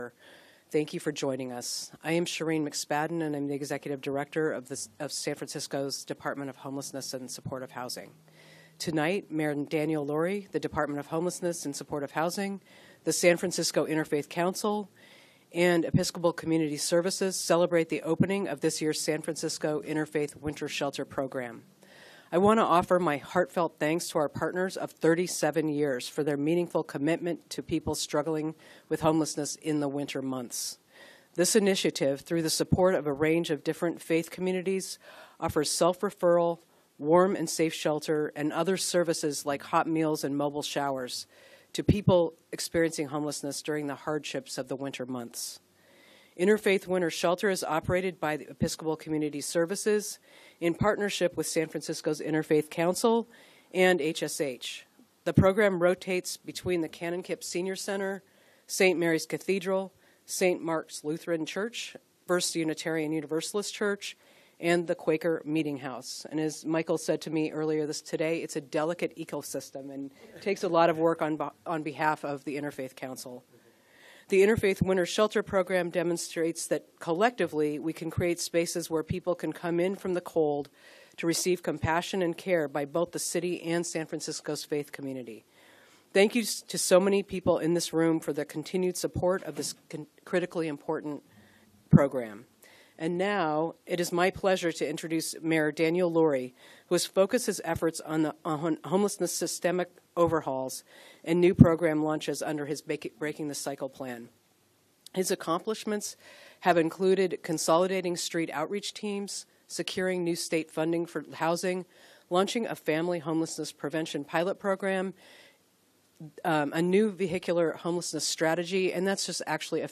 Mayor's Press Conference Audio